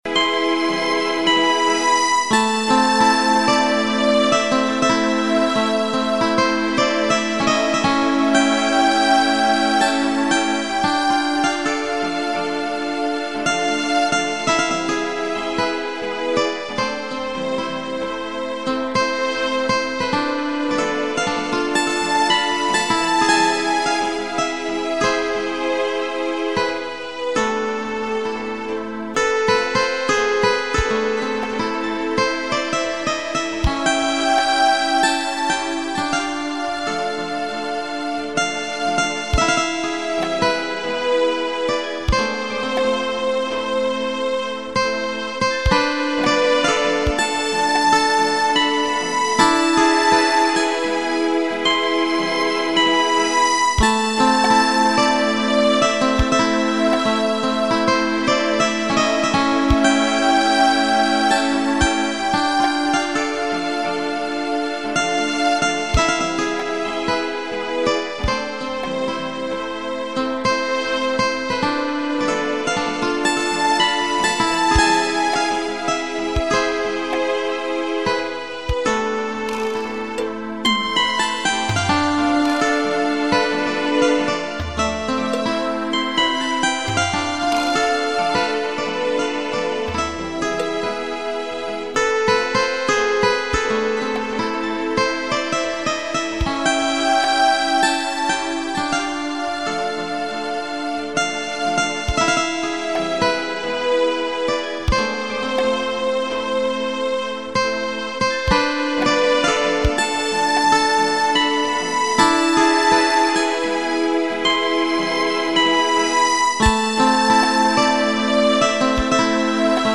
(романс)